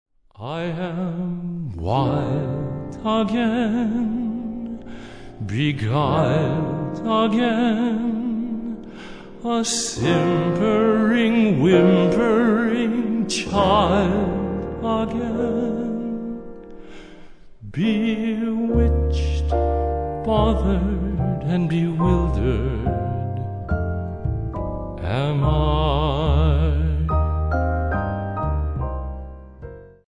a gentle voice
baritone
light, velvety, and sweet without ever being cloying